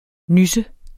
Udtale [ ˈnysə ]